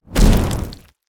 fire_punch_finisher_06.wav